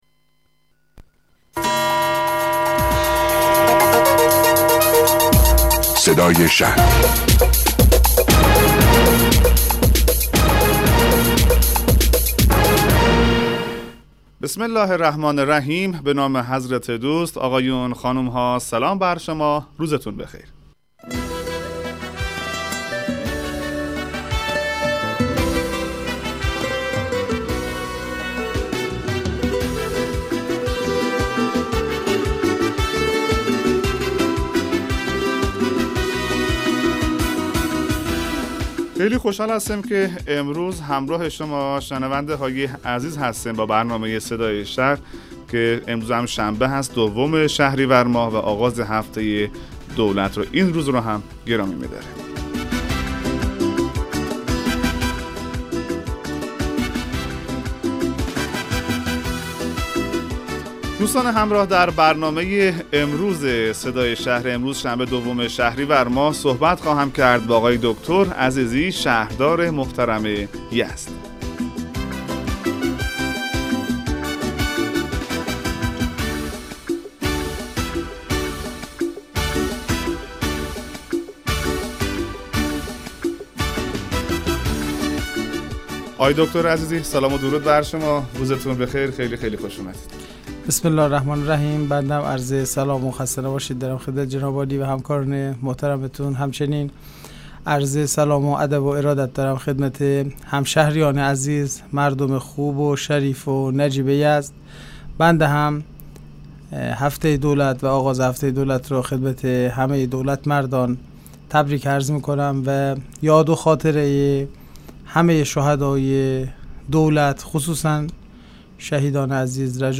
جمال‌الدین عزیزی بُندرآبادی؛ شهردار شهر میراث جهانی یزد درباره برنامه‌های شهرداری در هفته دولت، اقدامات شهرداری در راستای شناساندن یزد در عرصه جهانی، برندسازی یزد و اجلاس پیرغلامان حسینی توضیحاتی ارائه می‌دهد.